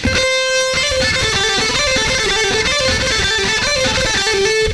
Il s'agit d'un exercice basé sur des notes liées, on utilise alors des hammer-on et des pull-off.